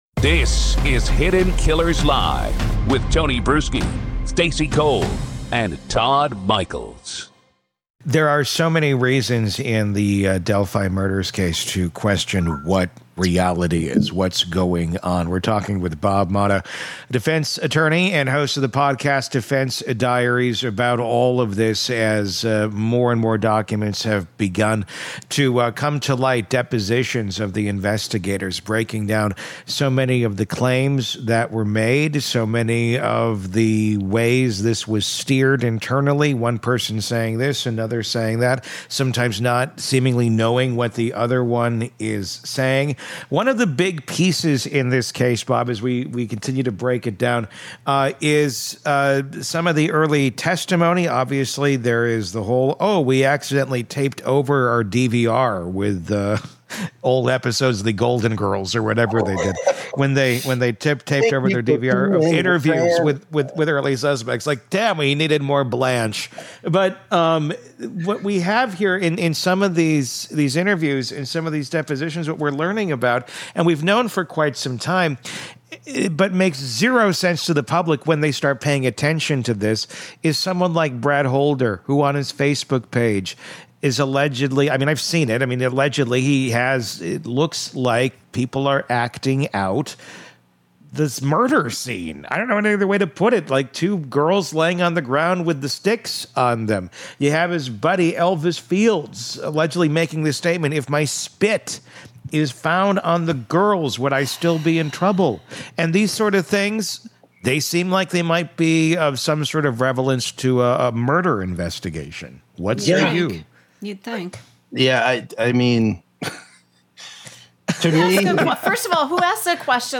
This interview digs into why these inconsistencies matter — not emotionally, but legally.